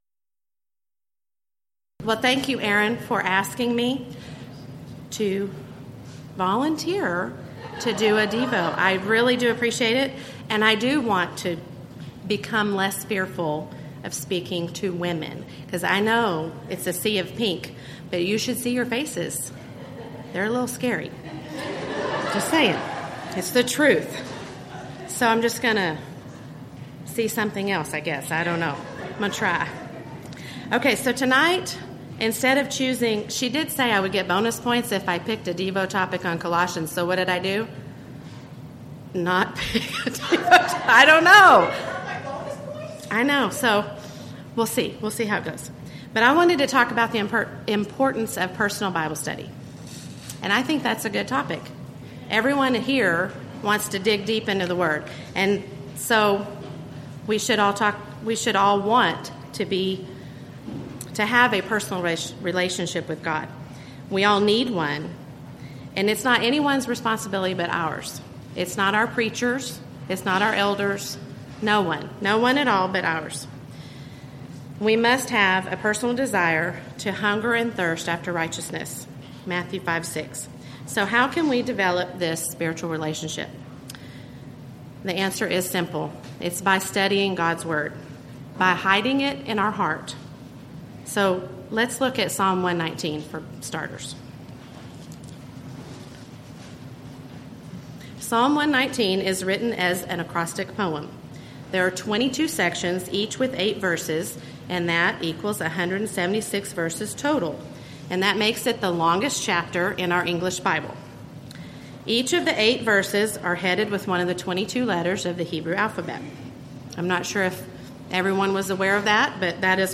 Title: Devotional 3
Event: 2014 Texas Ladies in Christ Retreat Theme/Title: Colossians & Philemon